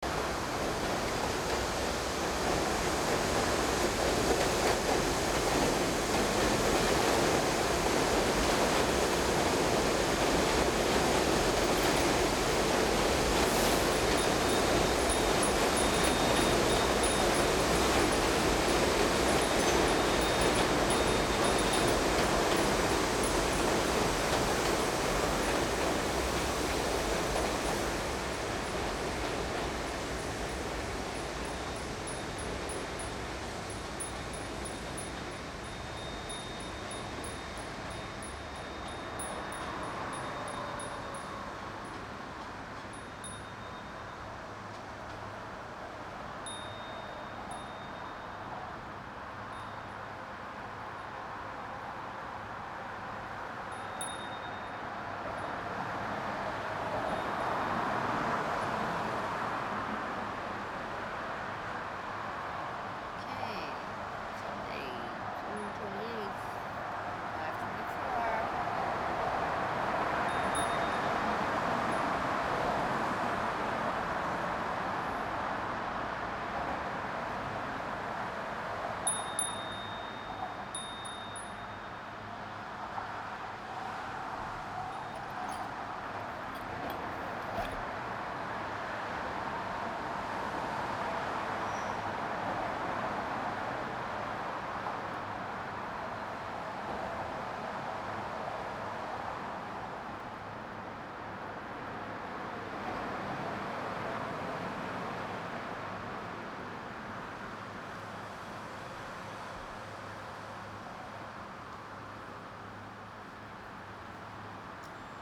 6/8 AM Sunday morning by the Williamsburg Bridge